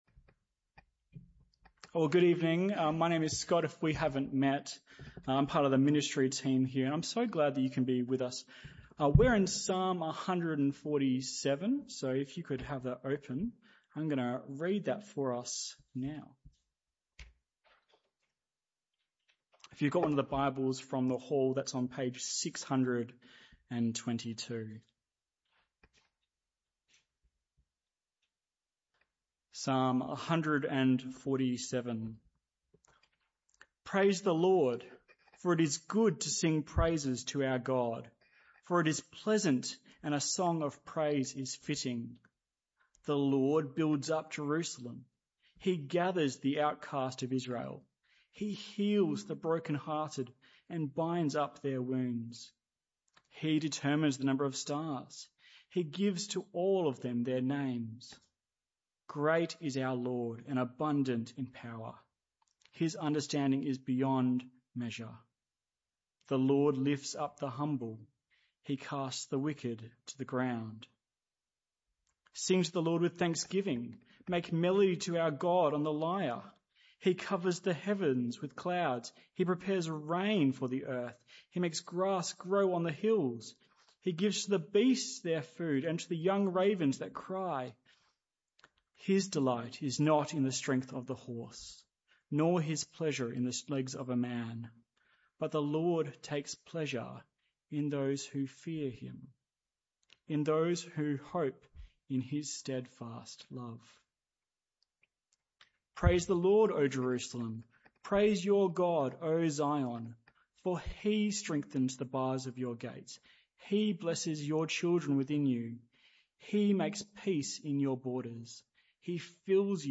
This talk was a one-off talk in the PM Service.